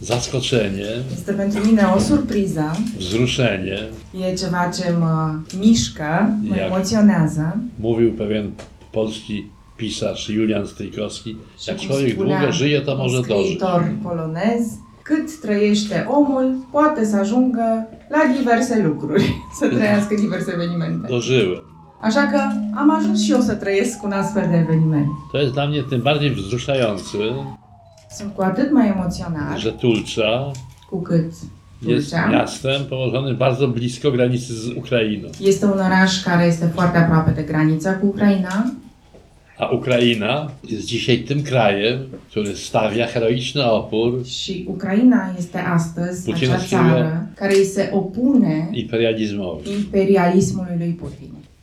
Ceremonia a avut loc la Primăria Municipiului Tulcea, în prezența primarului Ștefan Ilie, a reprezentanților comunității locale și ai mediului cultural. Distincția l-a emoționat pe Adam Michnik, care a subliniat semnificația acestui moment, trăit la Tulcea, atât de aproape de granița cu Ucraina.